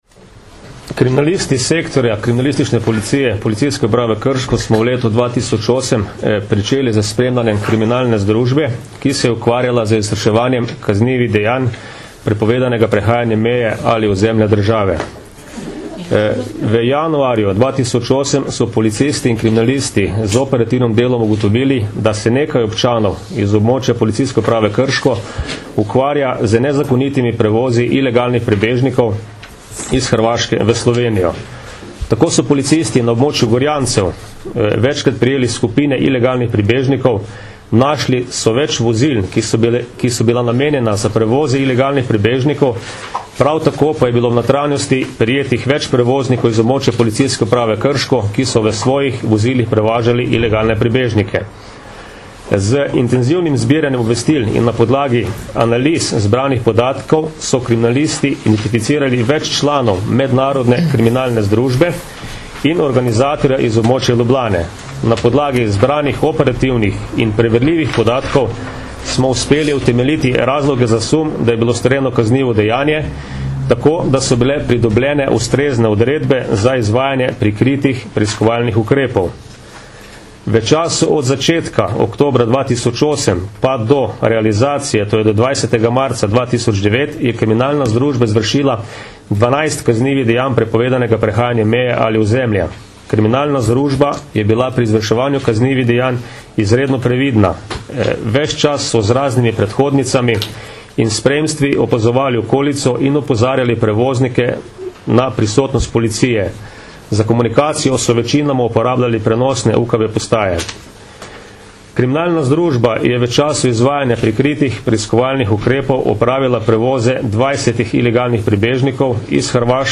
Uspešno zaključena večmesečna preiskava kaznivih dejanj prepovedanega prehajanja meje - informacija z novinarske konference
Zvočni posnetek izjave